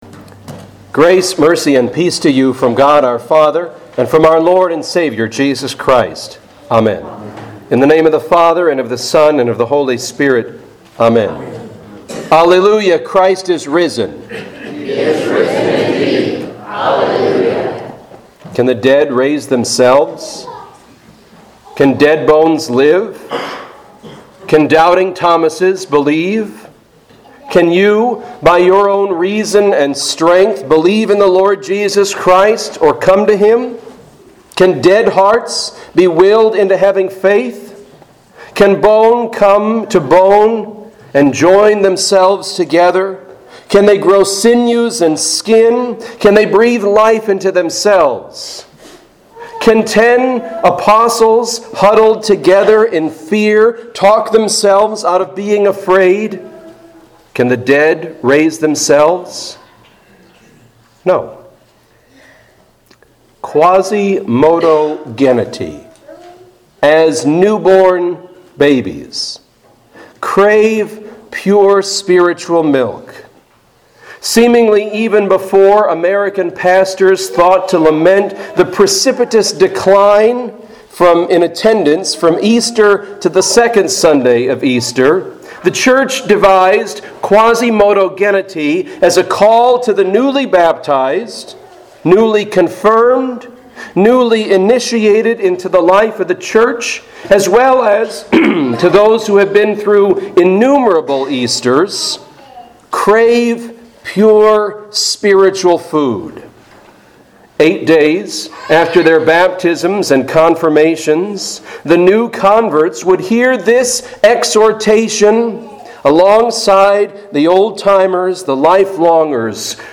Home › Sermons › Quasimodo Geniti-Easter 2